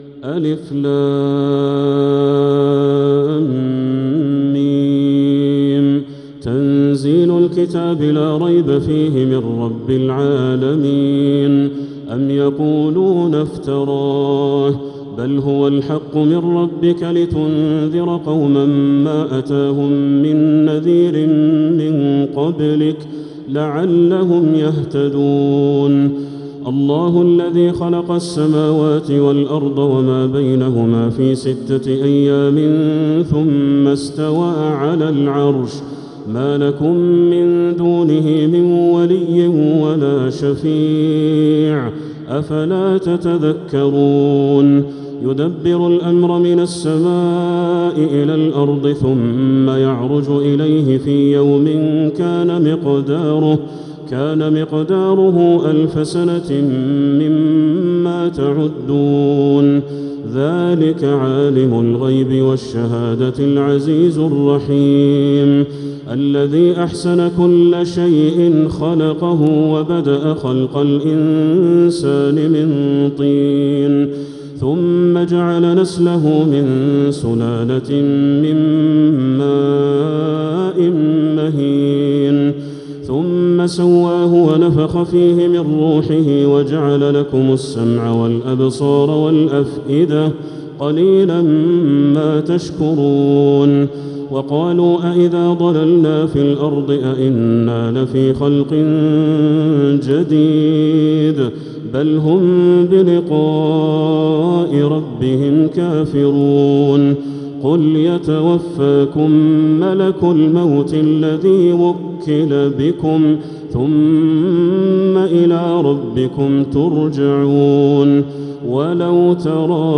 سورة السجدة كاملة | رمضان 1446هـ > السور المكتملة للشيخ بدر التركي من الحرم المكي 🕋 > السور المكتملة 🕋 > المزيد - تلاوات الحرمين